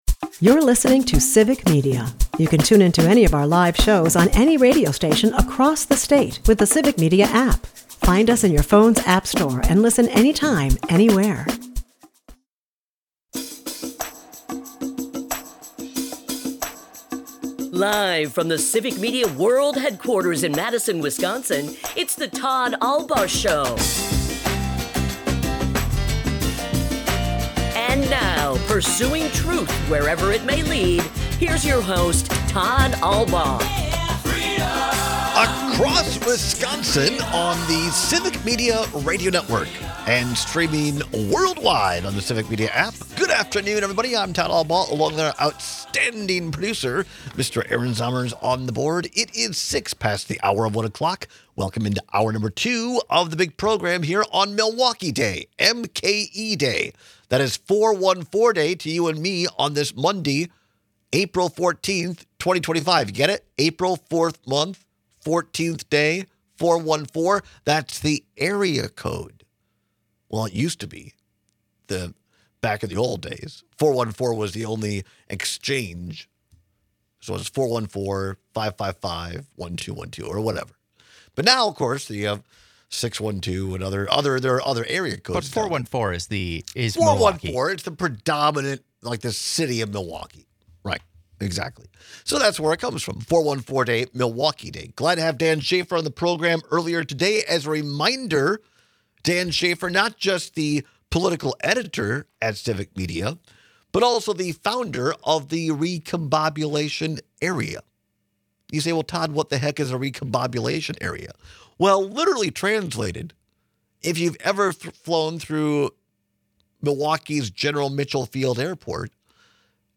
We take your texts and calls on the matter.